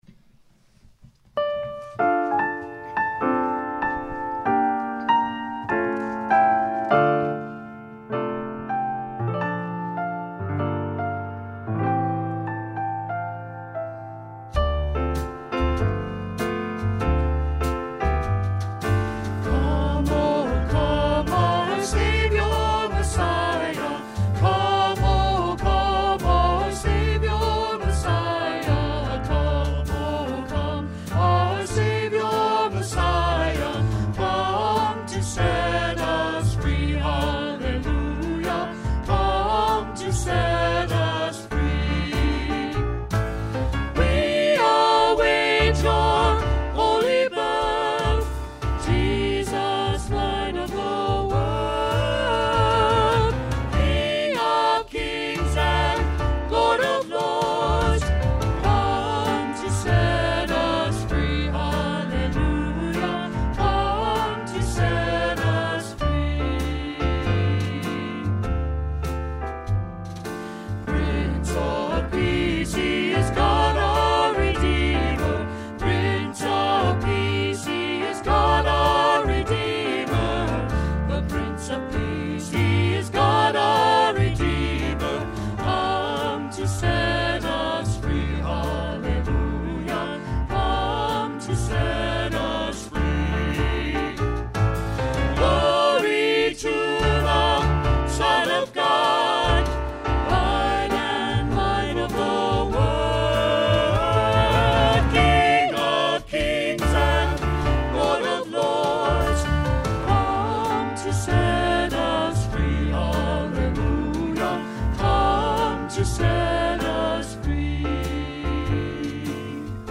Meditation 1; Music – Come to Set Us Free (Christmas Cantata 12.20.20 – Part 1) – Parkville Baptist Church